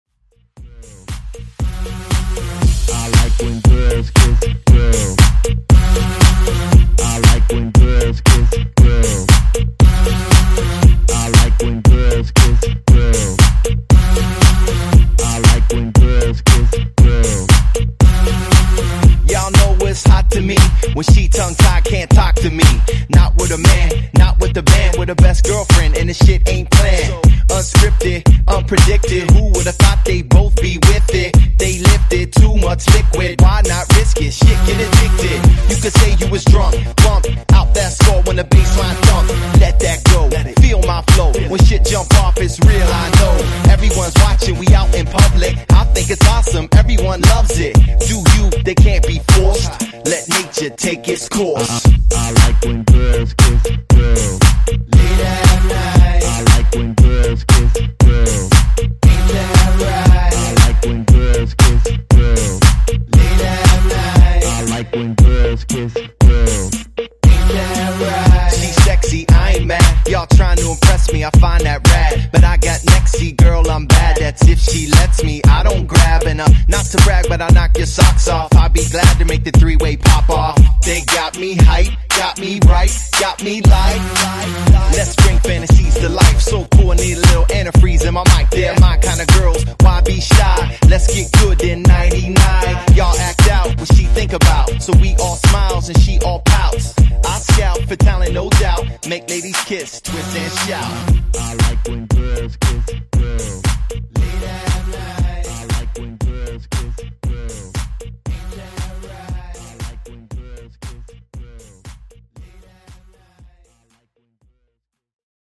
Genre: RE-DRUM
Clean BPM: 110 Time